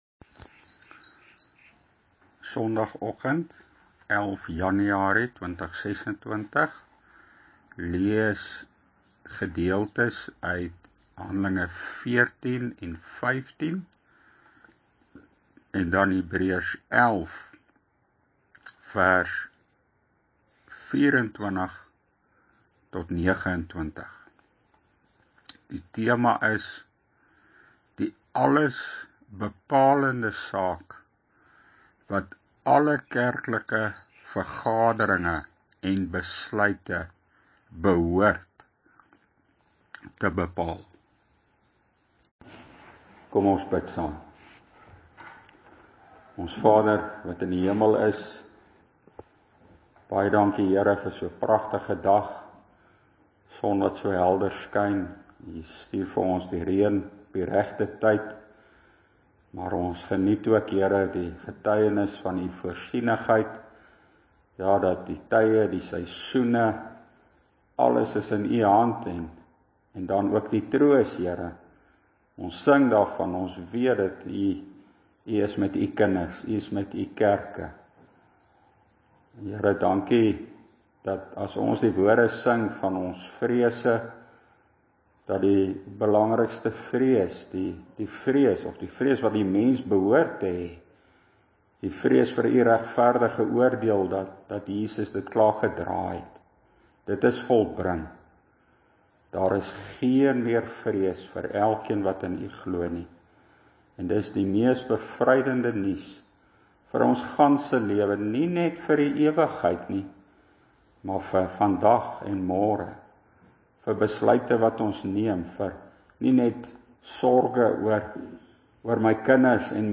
Hand. 15 & Hebr. 11 bekering- en reformasiepreek na Sinode 2026